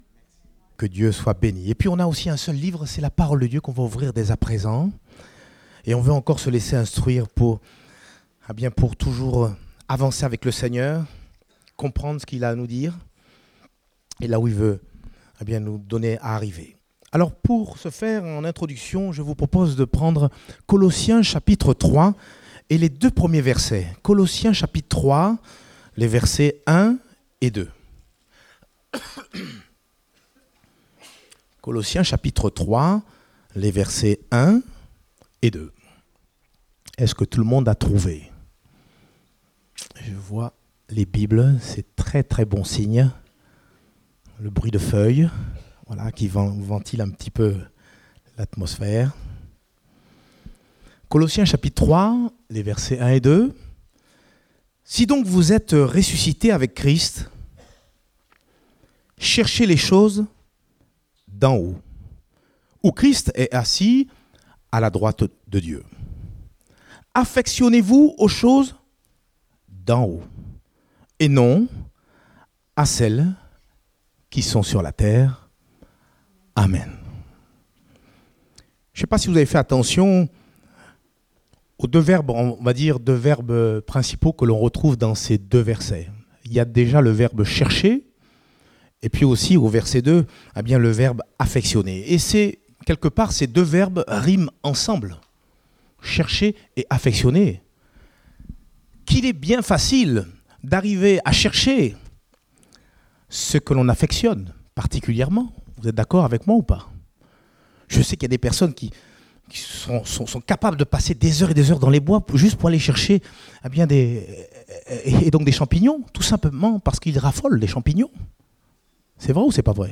Date : 28 octobre 2018 (Culte Dominical)